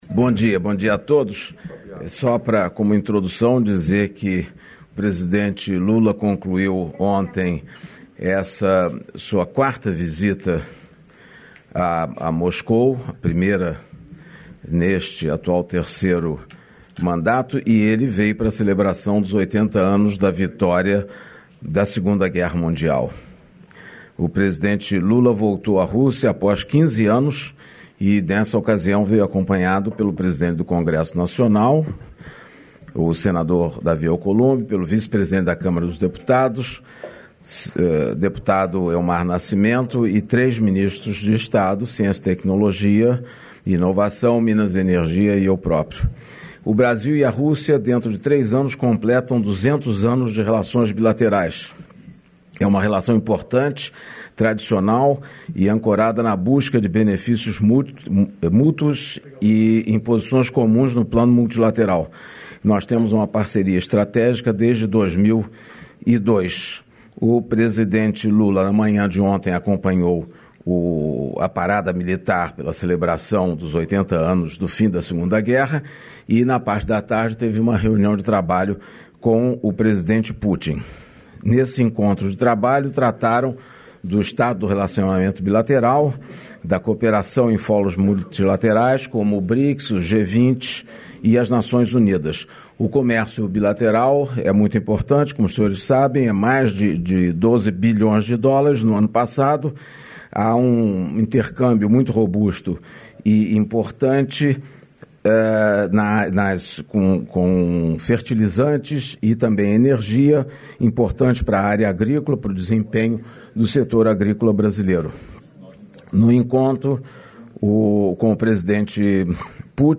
Íntegra da participação do presidente Lula na cerimônia de abertura do fórum “Um Projeto de Brasil”, nesta quarta-feira (14), na sede da Confederação Nacional da Indústria, em Brasília.